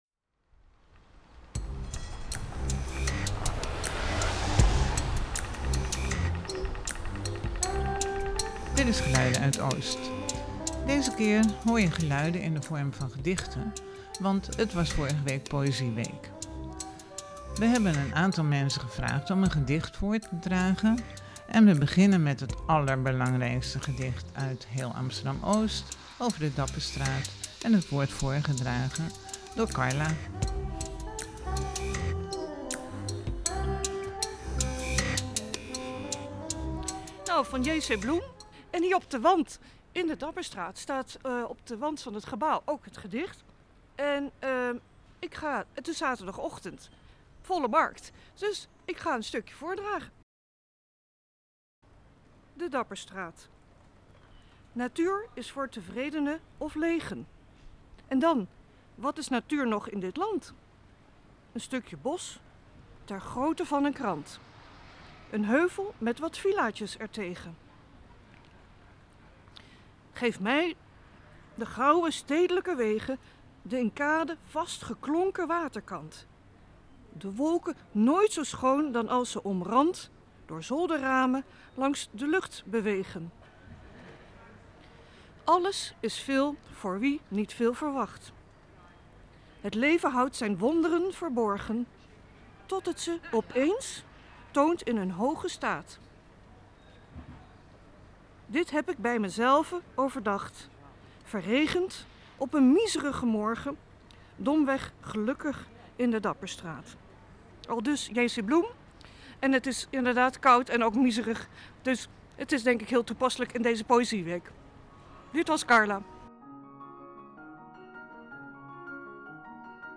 Ik draag er enkele gedichten voor, en spreek over mijn liefde voor Oost.